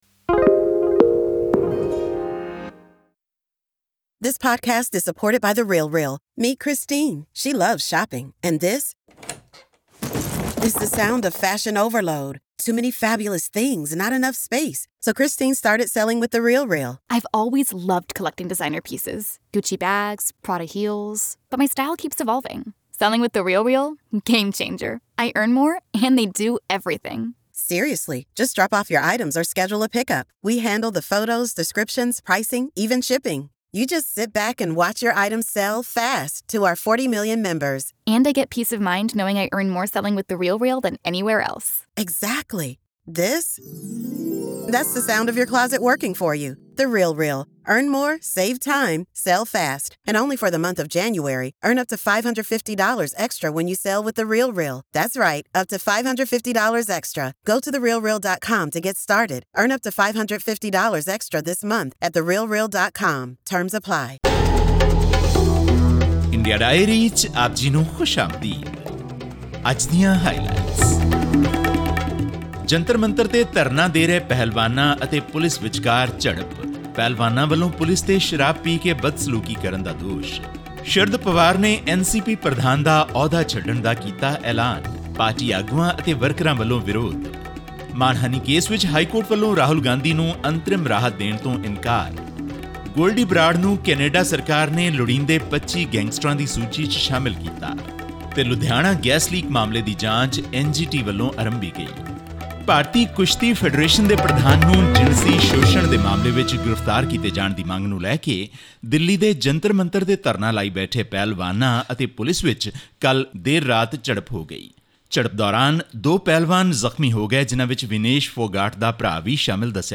ਪਹਿਲਵਾਨਾਂ ਦਾ ਦਾਅਵਾ ਹੈ ਕਿ ਪੁਲਿਸ ਅਧਿਕਾਰੀਆਂ ਨੇ ਉਨ੍ਹਾਂ ਨਾਲ ਦੁਰਵਿਵਹਾਰ ਤੇ ਫਿਰ ਹਮਲਾ ਕੀਤਾ। ਹੋਰ ਵੇਰਵੇ ਇਸ ਆਡੀਓ ਰਿਪੋਰਟ ਵਿੱਚ....